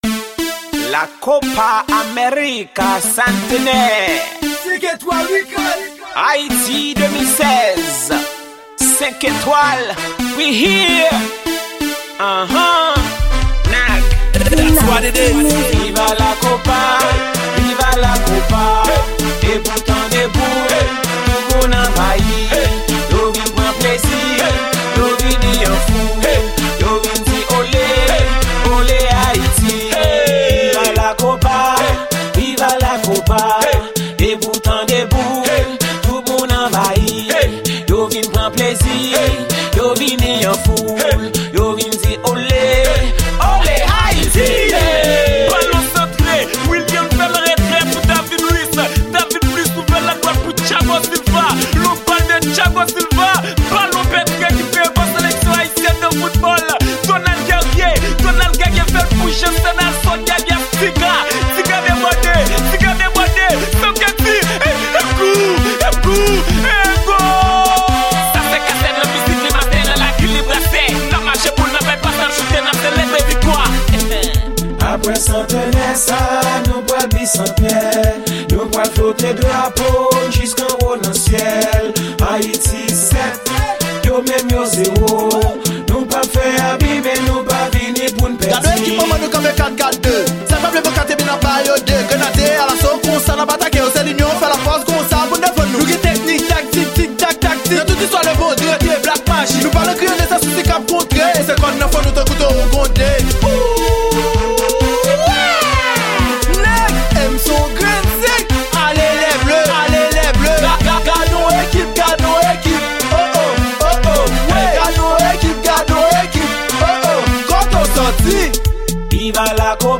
Genre: WORRLD.